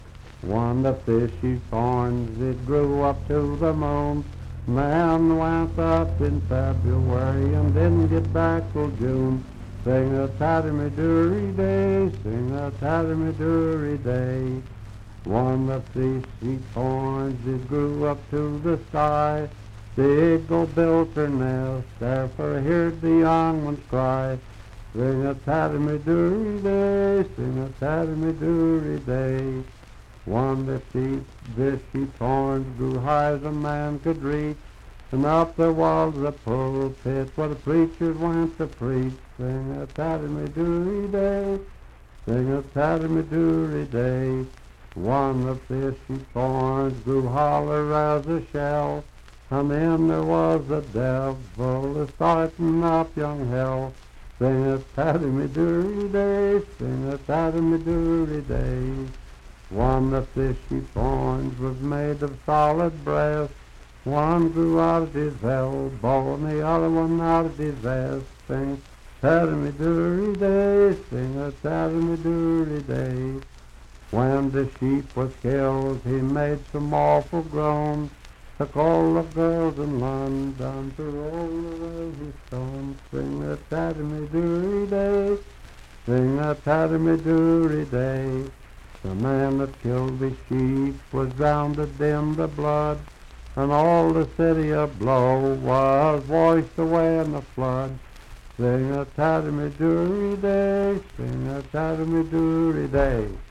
Unaccompanied vocal music
Bawdy Songs, Humor and Nonsense
Voice (sung)